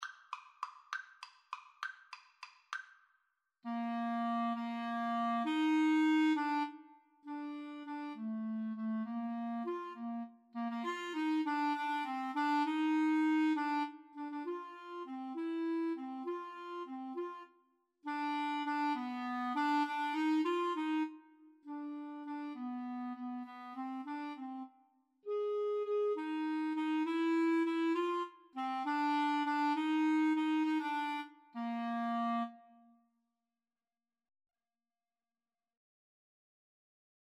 3/8 (View more 3/8 Music)
Classical (View more Classical Clarinet Duet Music)